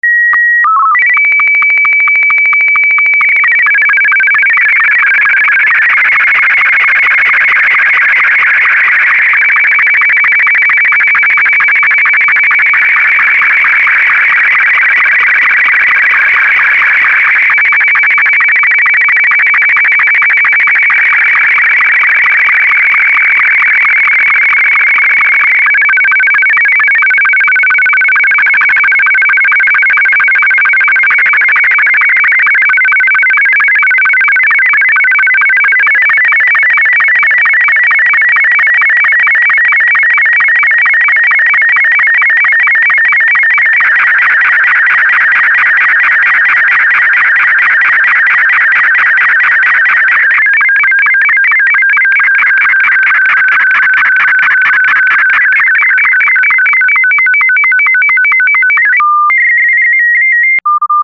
Digital Modes Demos and Identification
Here are examples of the more popular modes. Click on the speaker to hear the signal (in MP3) or click on the waterfall to see what it looks like.
SSTV-M2 (Analog format, Martin 2)
sstv-analog-m2.mp3